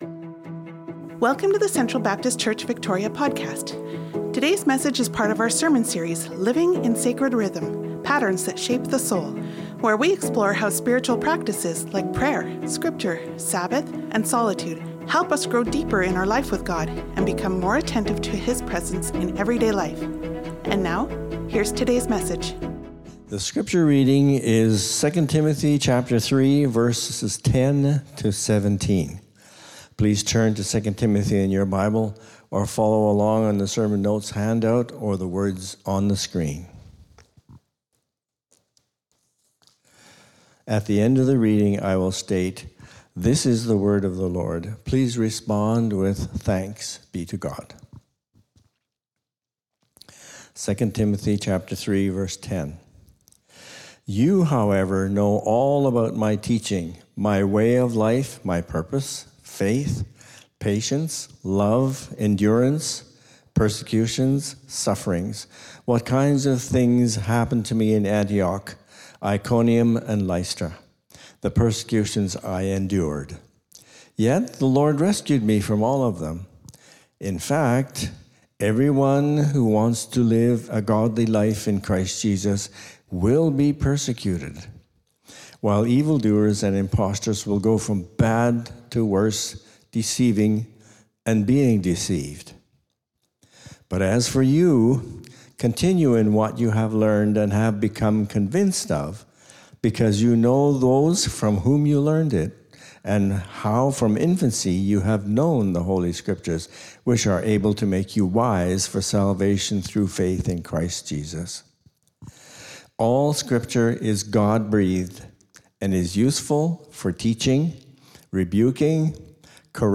Sermons | Central Baptist Church